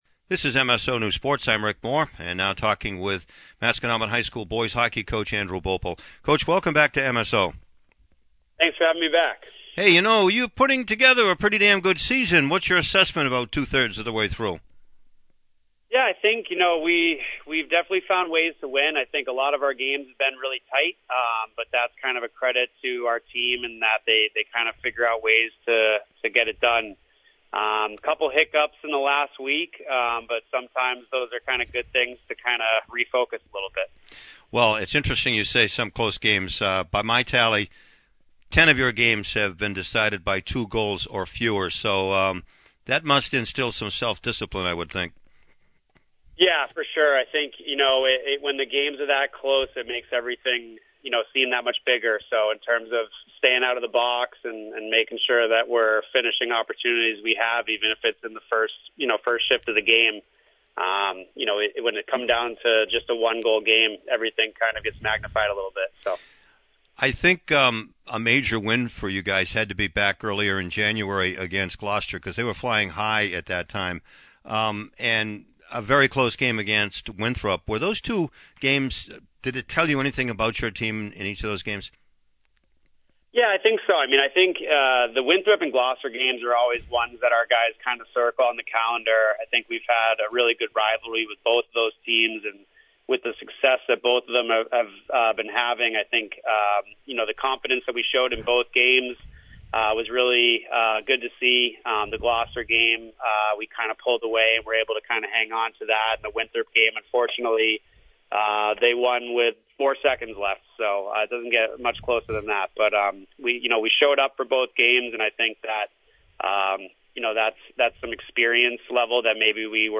Post-game, Pre-game